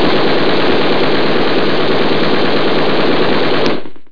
sparatoria.WAV